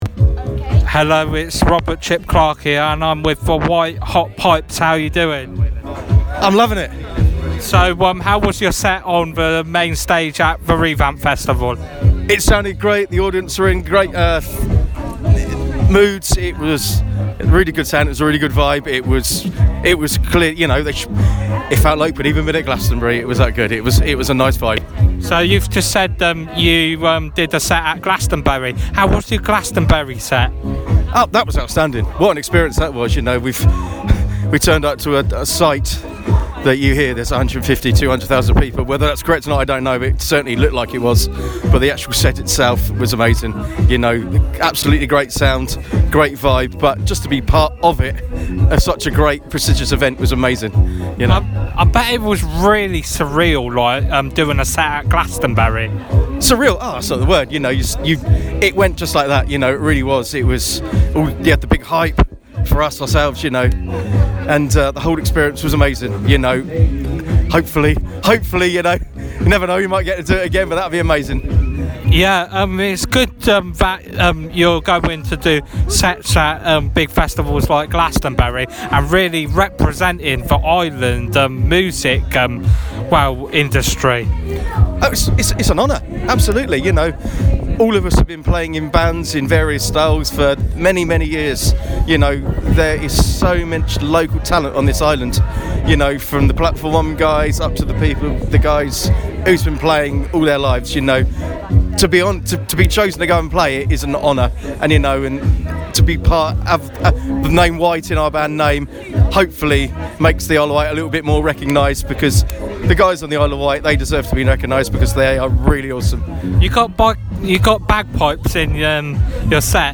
Wight Hot Pipes Revamp Interview 2019